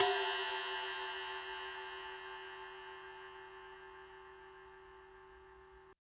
Index of /90_sSampleCDs/Roland L-CD701/CYM_Rides 1/CYM_Ride Modules